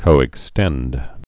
(kōĭk-stĕnd)